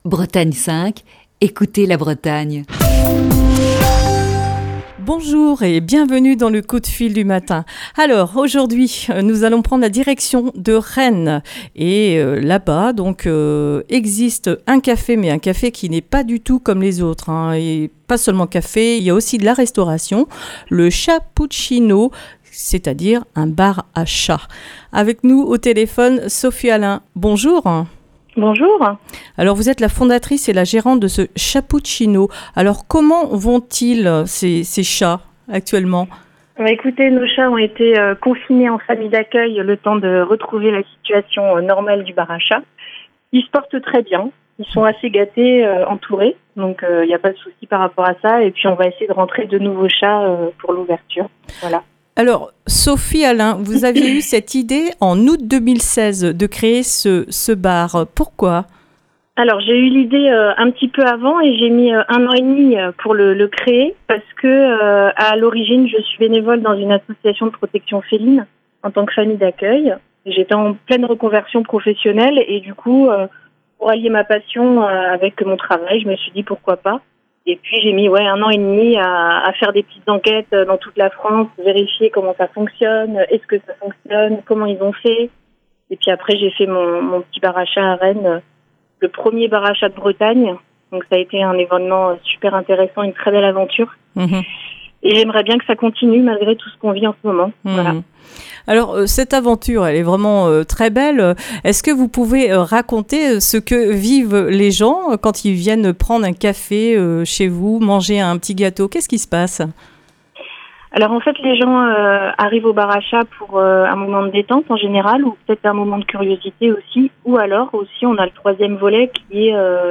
Coup de fil du matin